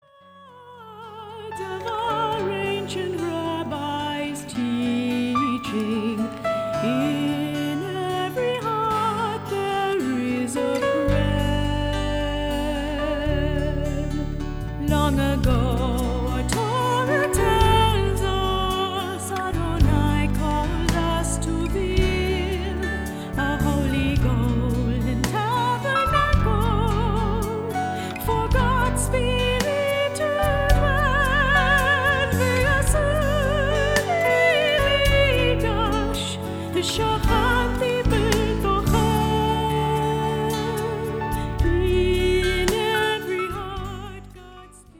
Inspirational music